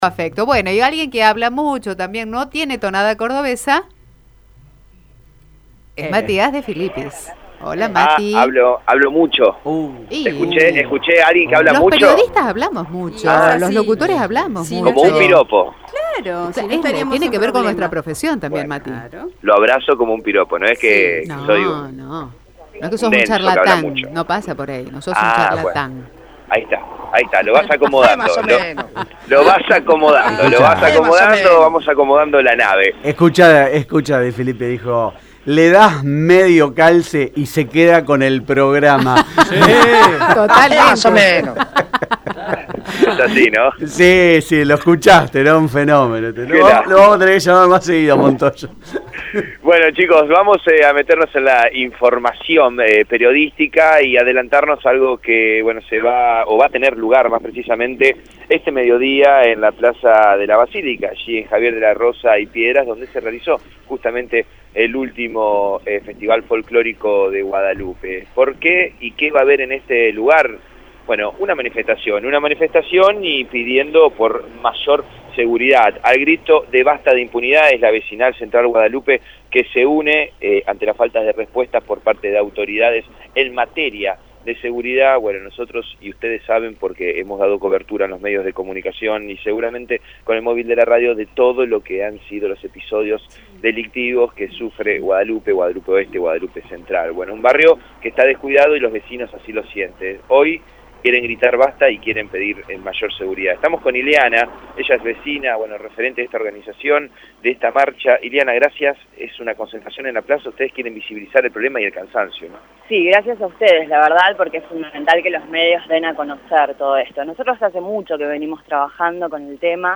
Vecina de Guadalupe en Radio EME